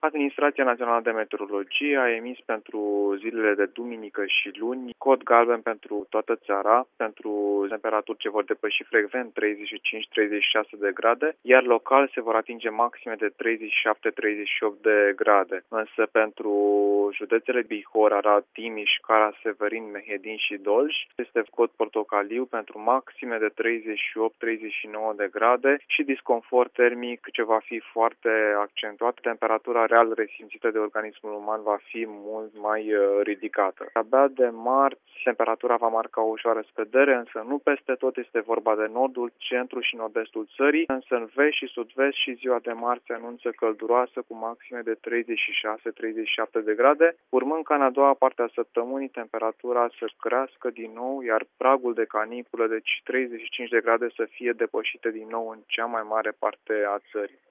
Meteorologul